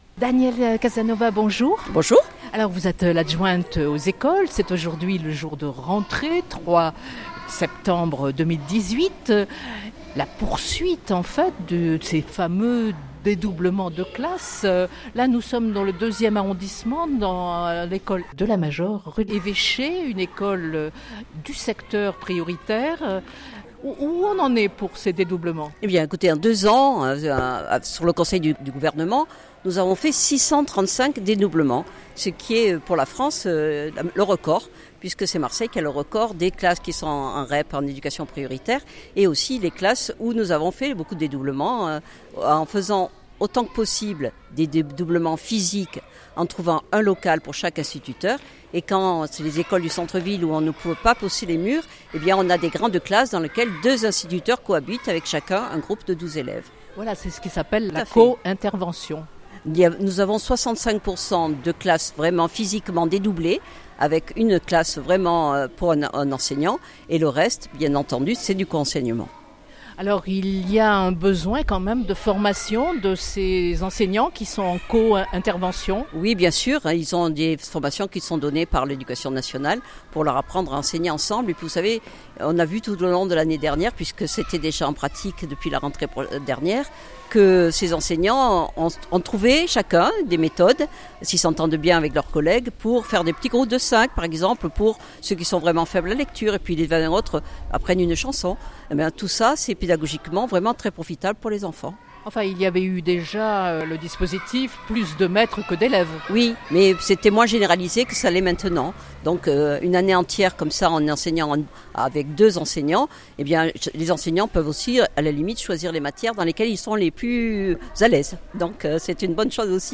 son_copie_petit-263.jpgDanièle Casanova, adjointe au maire de Marseille en charge des écoles et du soutien scolaire, était dans la cour de l’École élémentaire La Major à Marseille (2e) lors de la rentrée scolaire. L’élue est revenue sur le dédoublement des classes de CP et de CE1 des écoles du Réseau d’éducation prioritaire de Marseille.